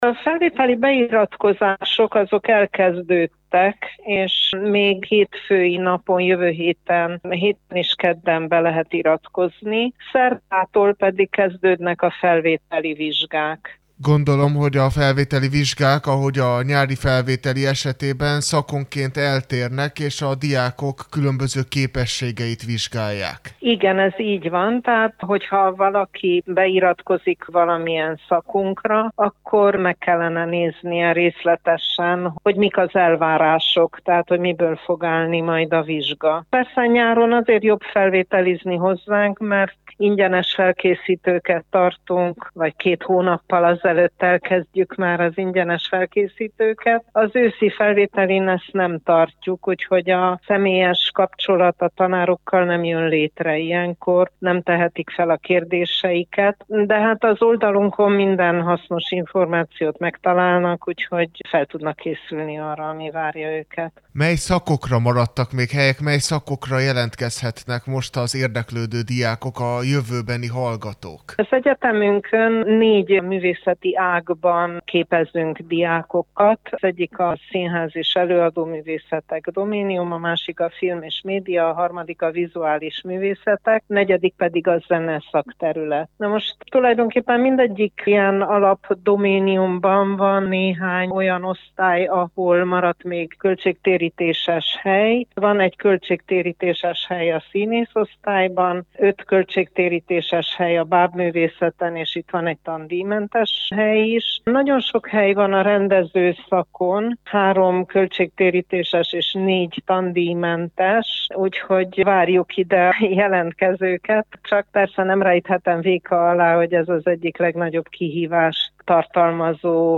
beszélgetett